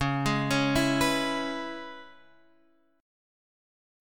C#m7 chord